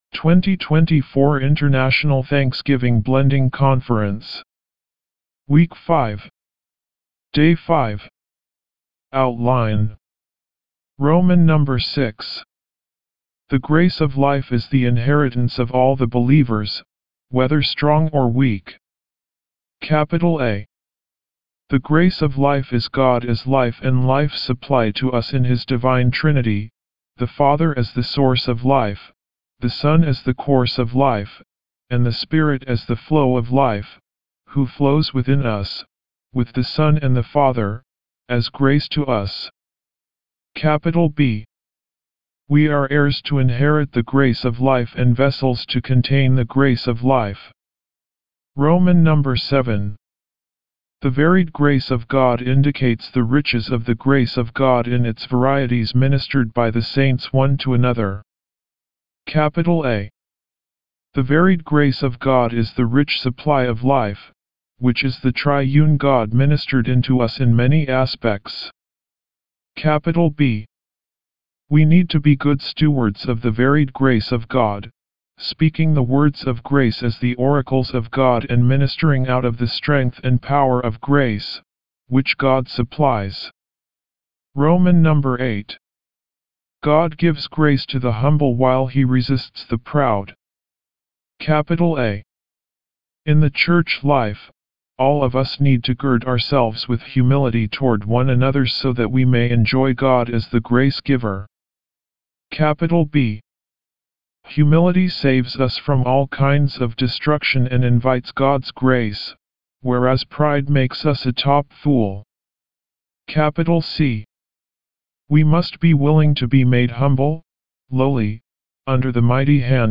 D5 English Rcite：